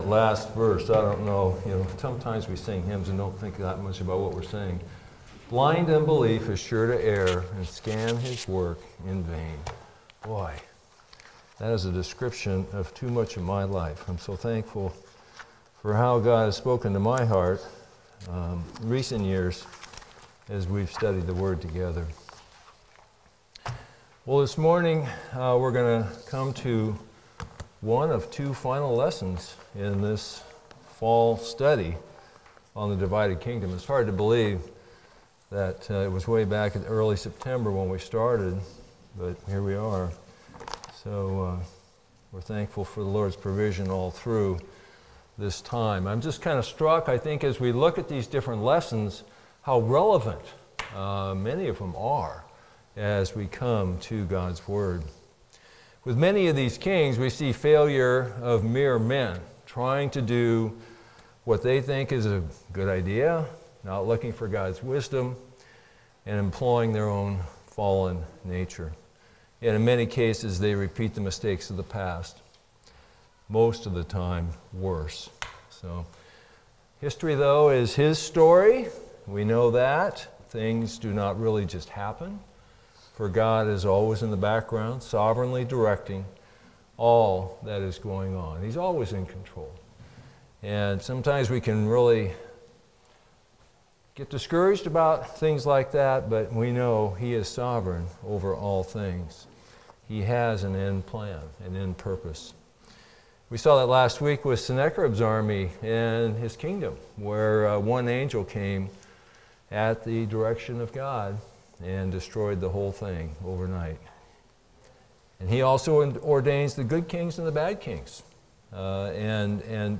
Passage: 2 Kings 21-23 Service Type: Sunday School